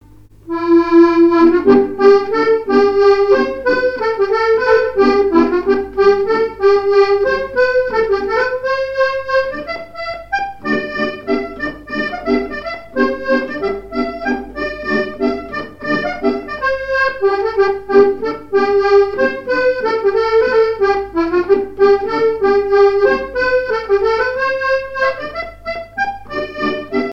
Chants brefs - A danser
danse : mazurka
airs de danse à l'accordéon diatonique
Pièce musicale inédite